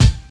Clicky Kick Drum Sample A# Key 293.wav
Royality free bass drum sound tuned to the A# note. Loudest frequency: 754Hz
clicky-kick-drum-sample-a-sharp-key-293-2TO.mp3